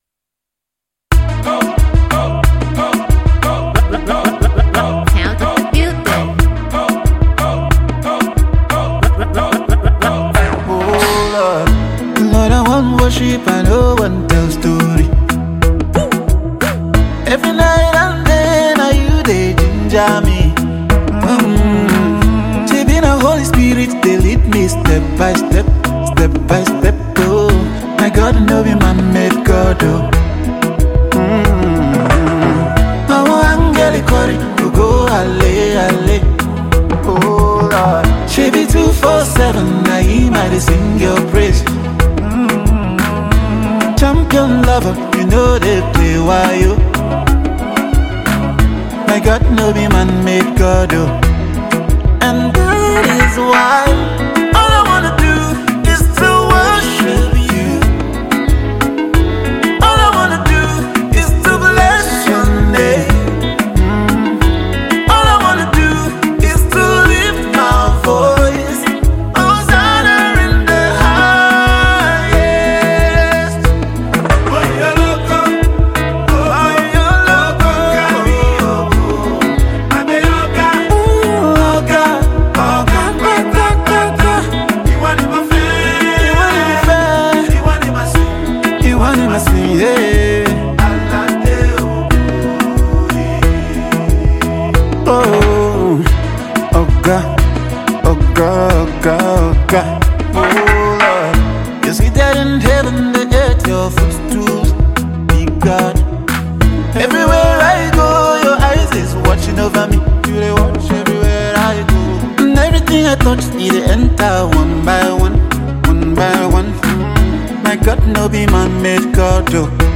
Category: Gospel Music Genre: Afrobeats